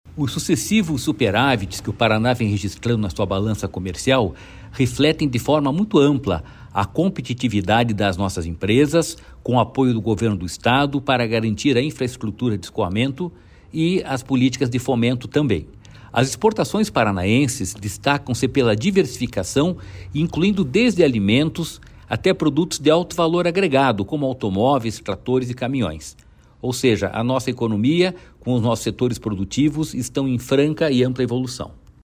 Sonora do presidente do Ipardes, Jorge Callado, sobre o Paraná acumular cinco superávits desde 2019